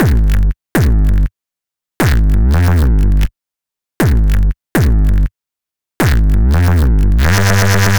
guitar amp reese.wav